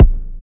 KICK 15.wav